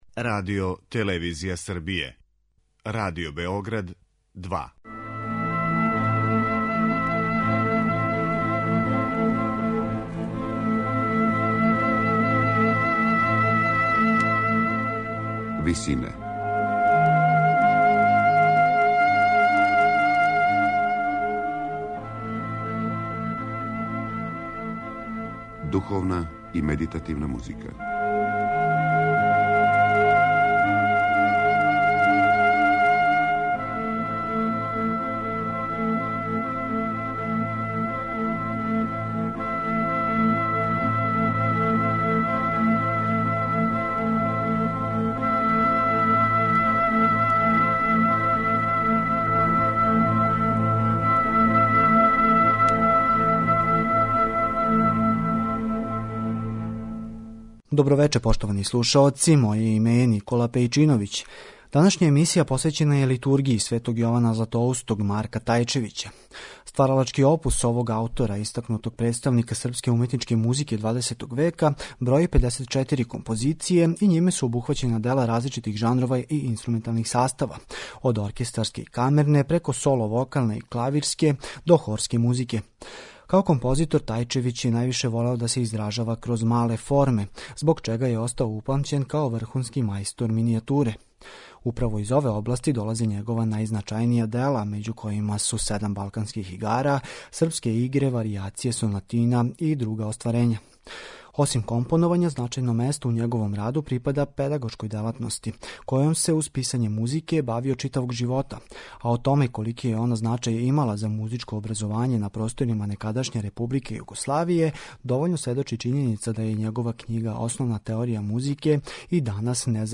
Емисија о духовној и медитативној музици